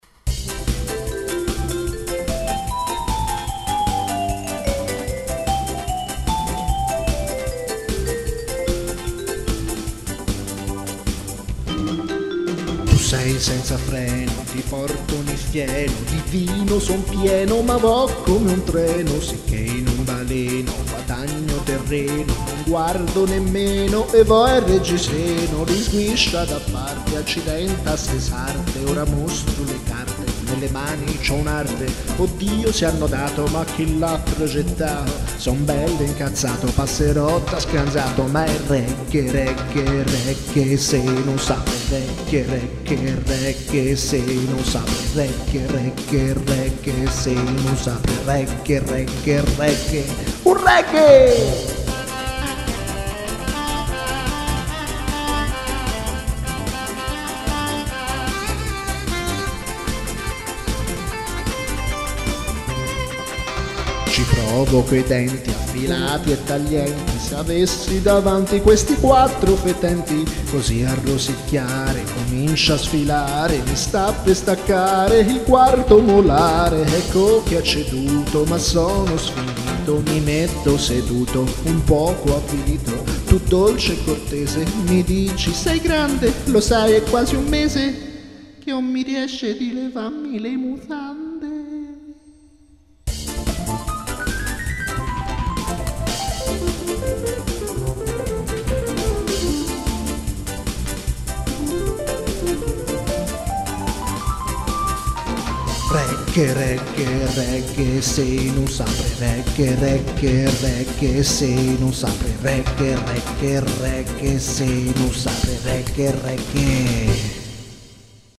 Un pezzo regghe su i regiseni che non si aprono